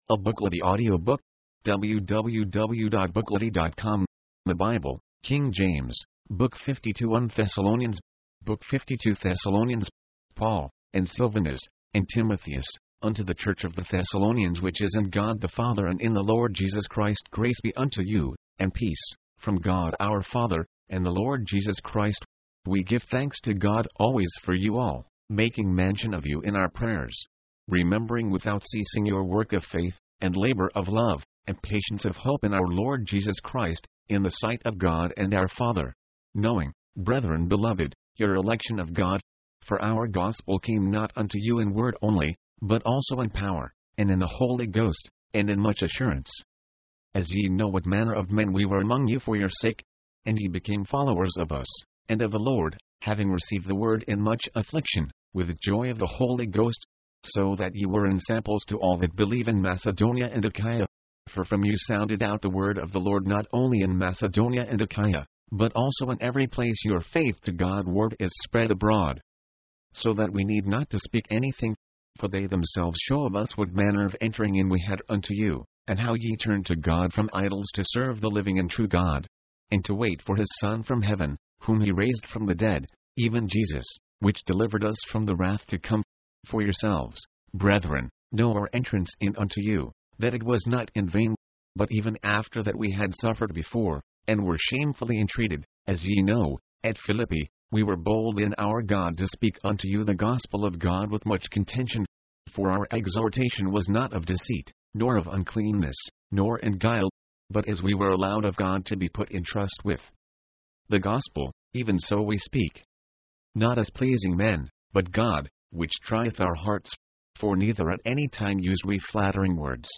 Paul concludes by exhorting them to watch for the Day of the Lord, which will come like a thief in the night, and to abstain from evil. mp3, audiobook, audio, book